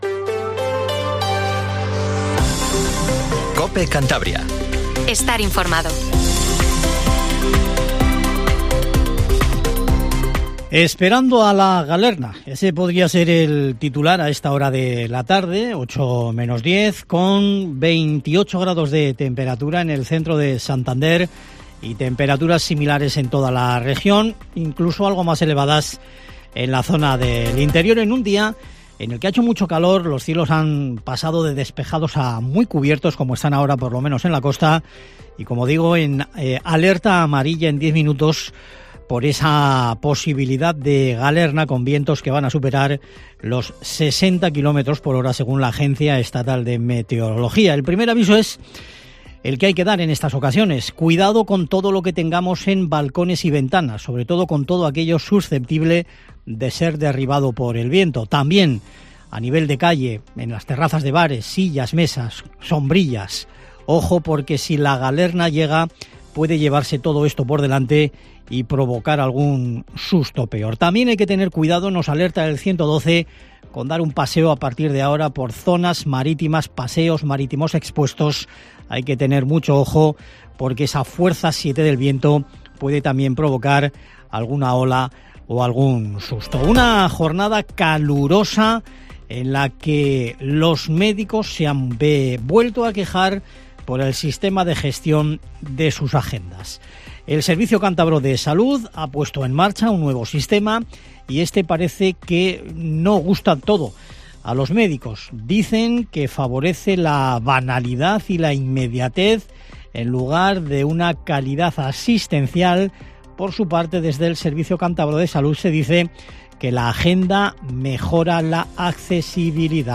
Informativo Tarde COPE CANTABRIA